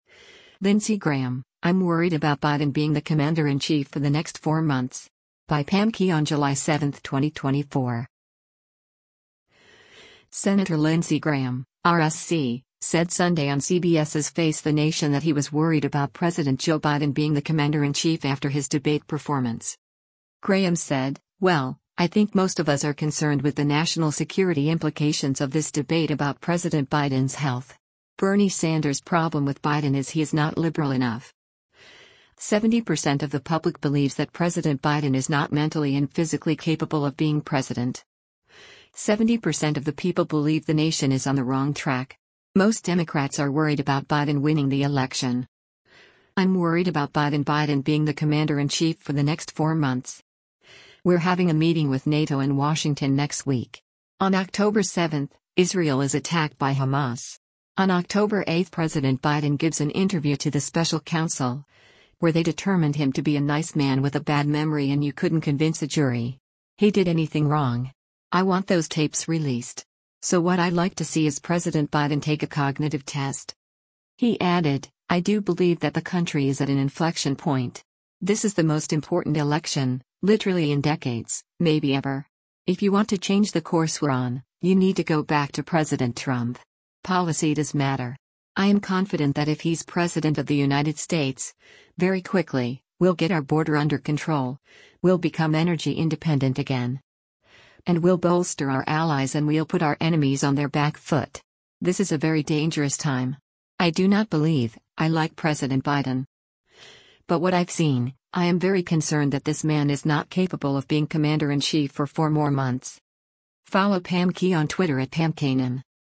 Senator Lindsey Graham (R-SC) said Sunday on CBS’s “Face the Nation” that he was worried about President Joe Biden being the commander-in-chief after his debate performance.